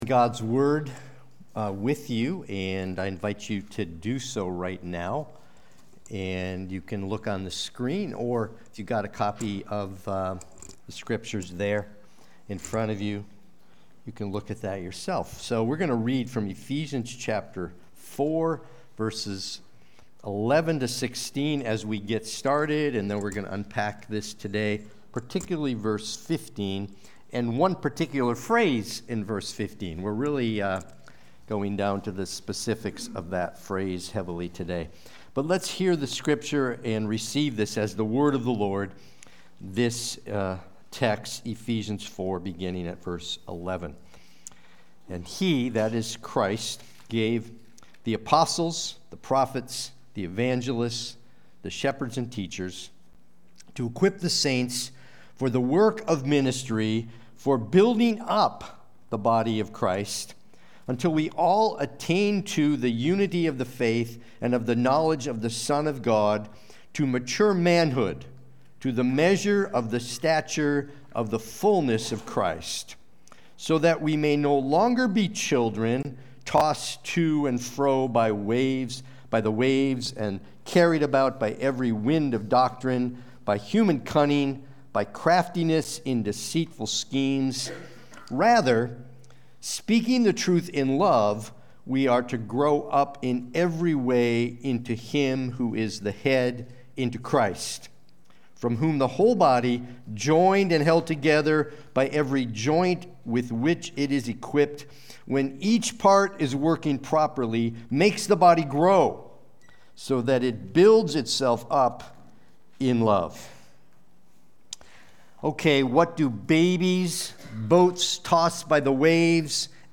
Watch the replay or listen to the sermon.
Sunday-Worship-main-72025.mp3